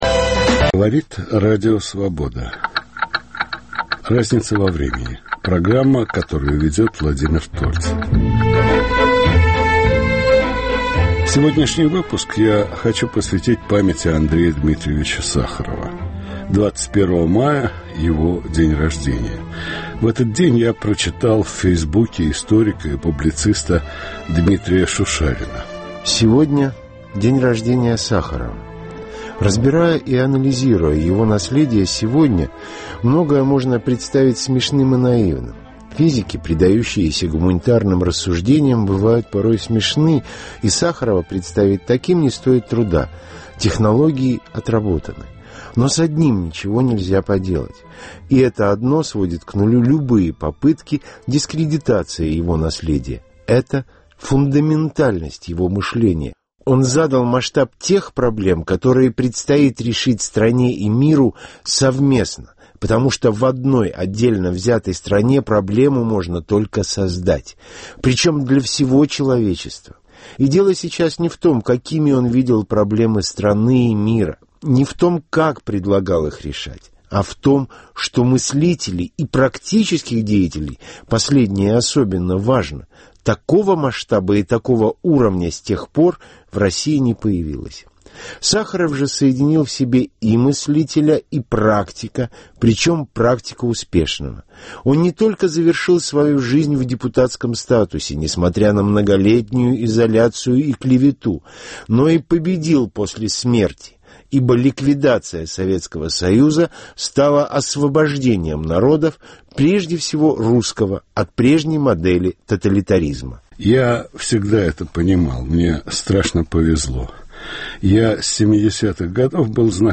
1. 92-й день рождения Сахарова. 2. Андрей Сахаров: мир идей. (архивные записи 1990-х и 2005 гг.)